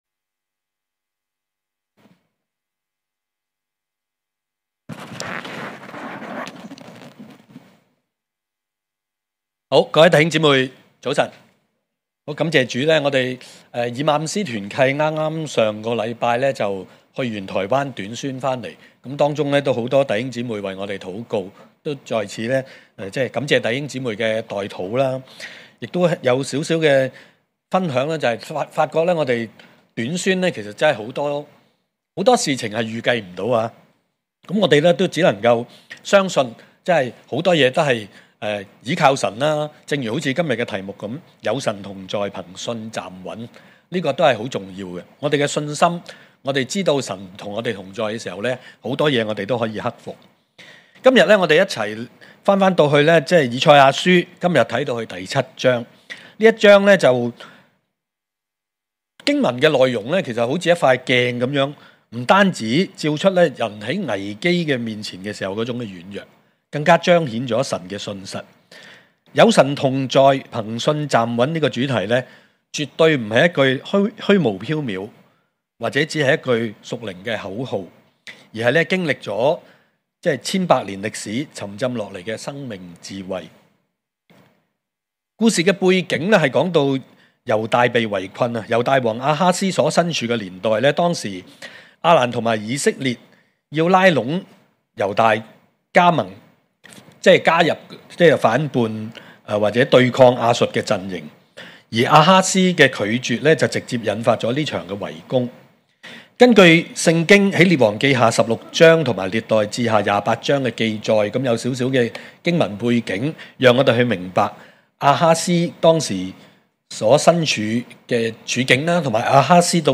證道集
恩福馬鞍山堂崇拜-早、午堂